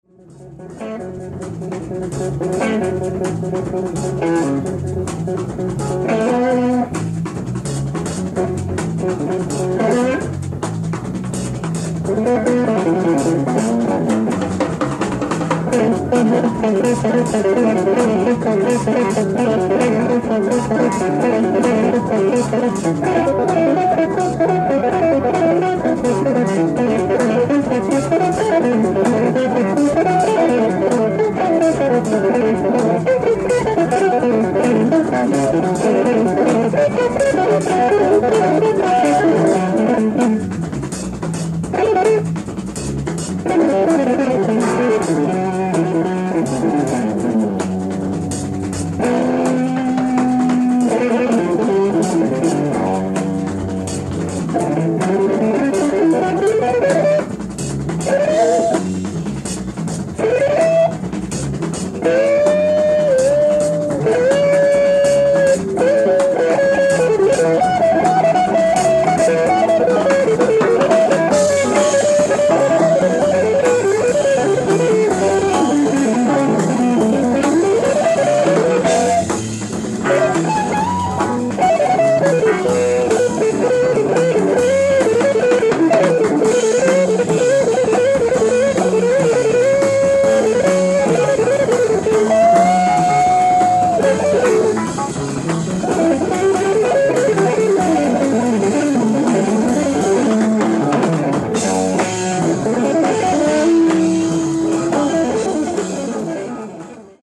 ライブ・アット・ジャバウォーキー・クラブ、シラキューズ、ニューヨーク 11/04/1971
ステレオ・サウンドボード収録！！
※試聴用に実際より音質を落としています。